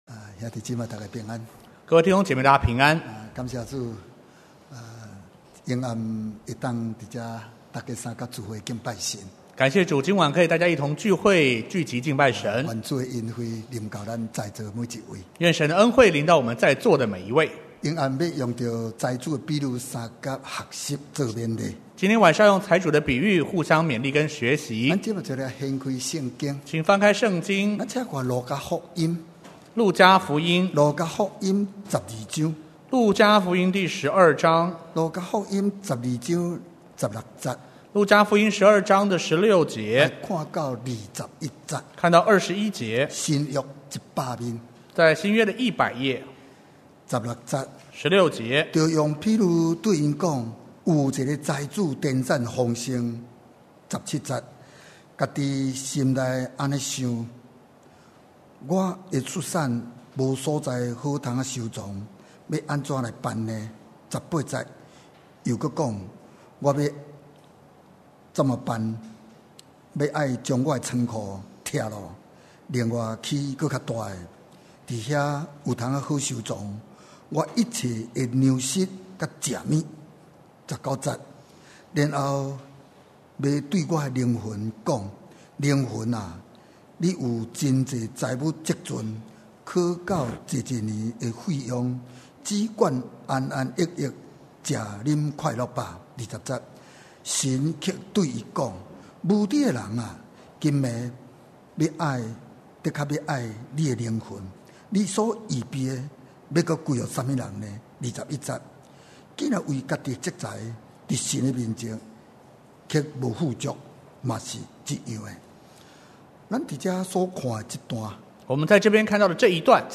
2017年4月份講道錄音已全部上線
(詩歌佈道會)